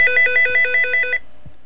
1 channel
phnring.mp3